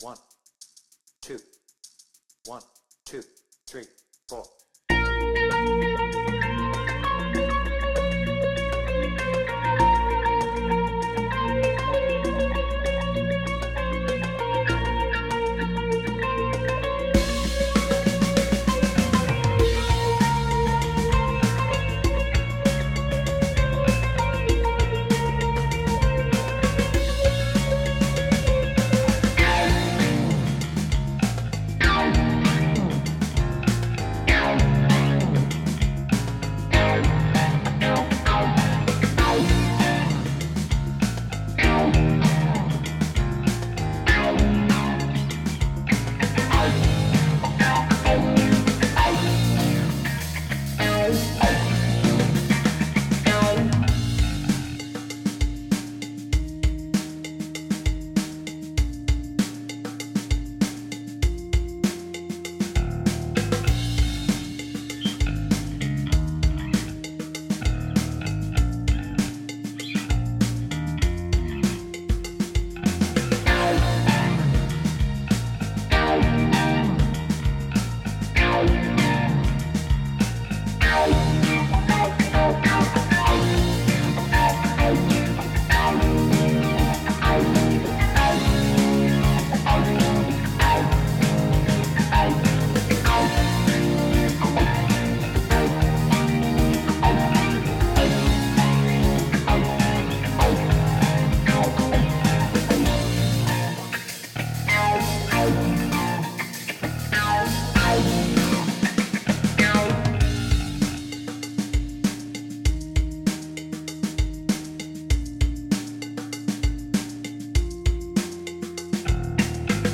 BPM : 98
Without vocals